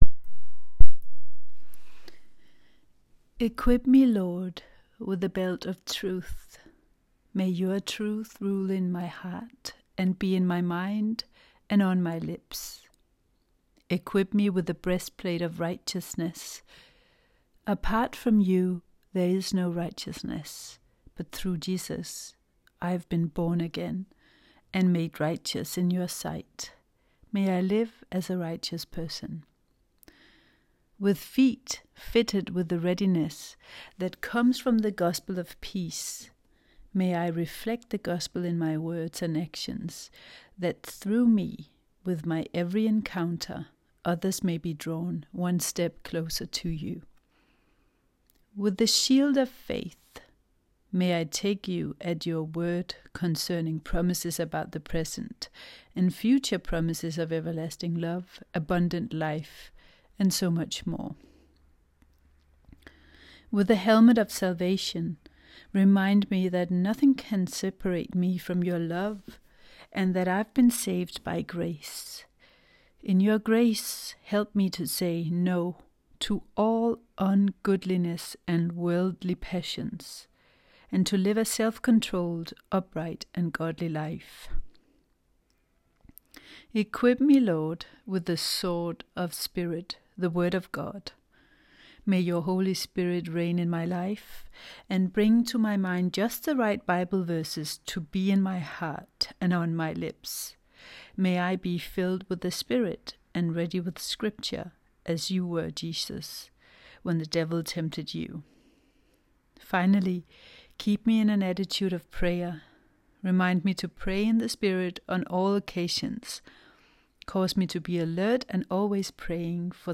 PRAYER TO PUT ON THE ARMOUR OF GOD
theta wave
Armour-of-God-prayer.m4a